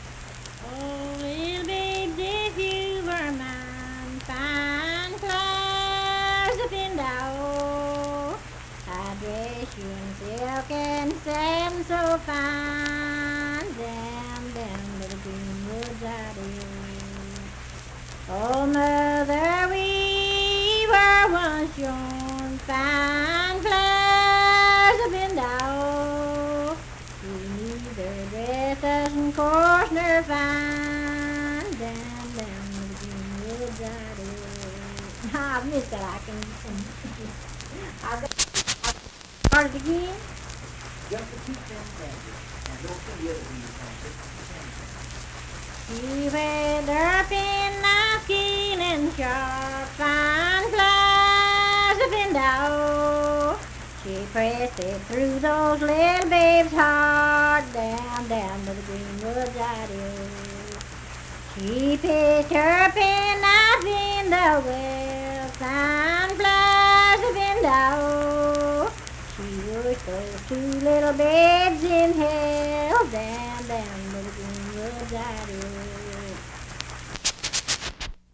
Unaccompanied vocal music
Voice (sung)